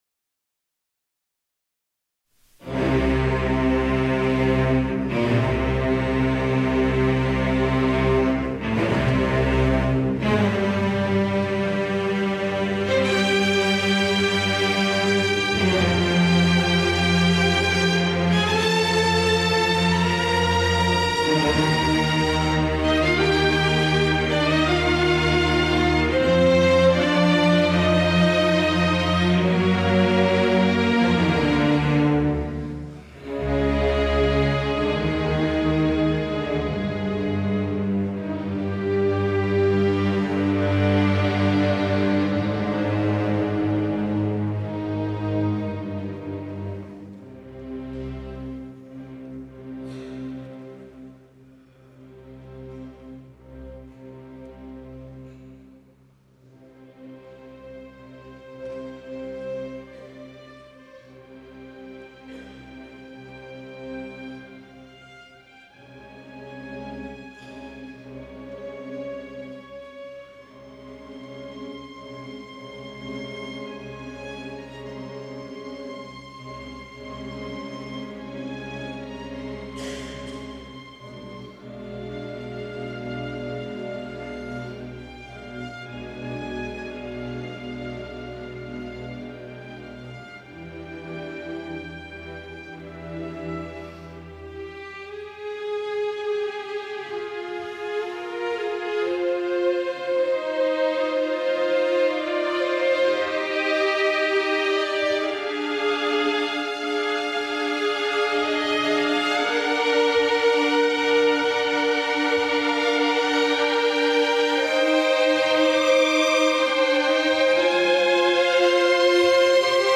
Концертная запись.